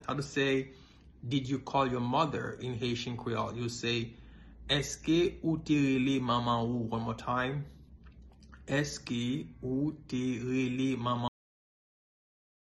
Pronunciation:
Did-you-call-your-mother-in-Haitian-Creole-Eske-ou-te-rele-manman-ou-pronunciation.mp3